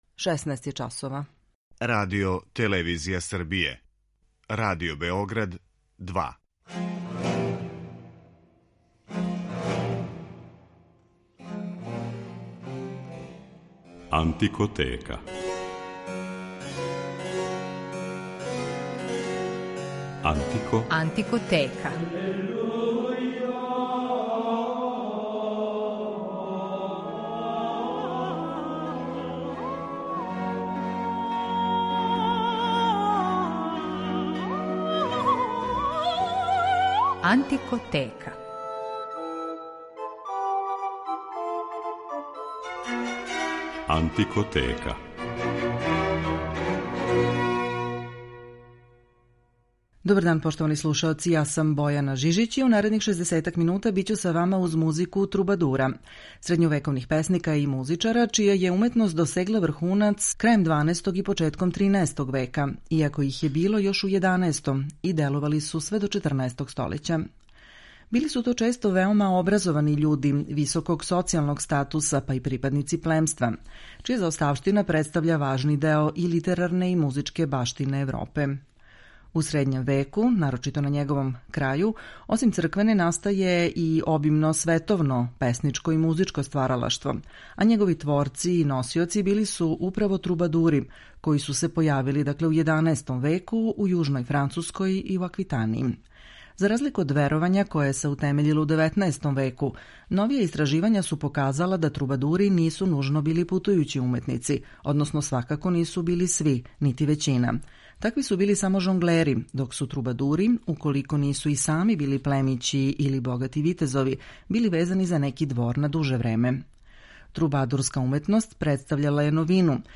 Управо из овог периода су и шансоне које ћете моћи да слушате.